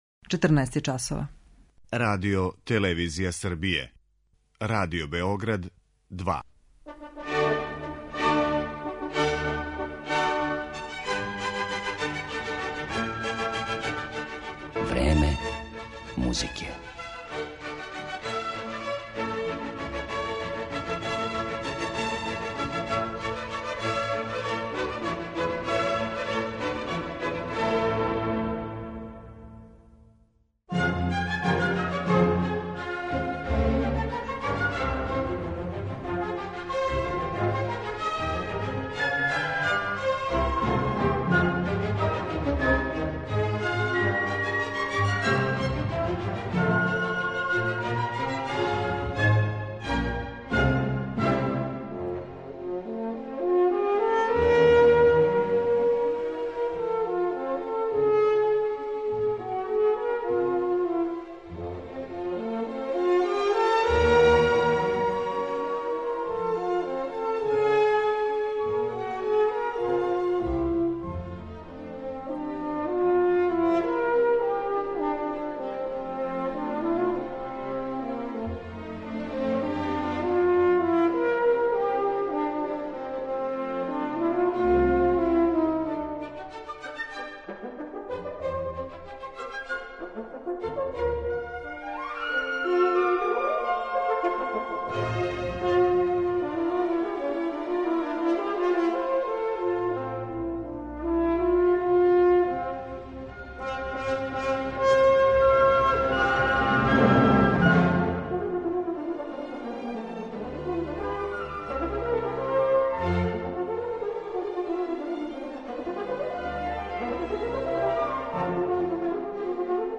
Један од технички најзахтевнијих дувачких инструмента јесте хорна, а један од најбољих извођача на њој био је Херман Бауман, коме је посвећена данашња емисија.